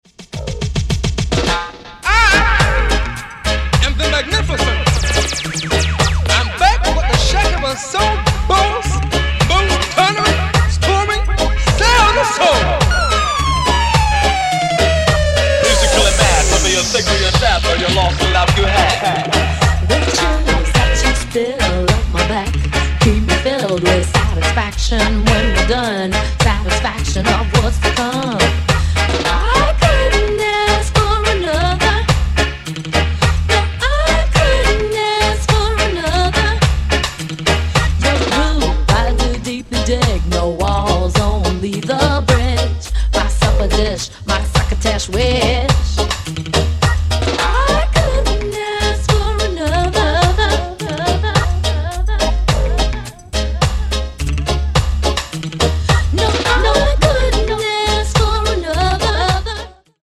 explosive reggae mash up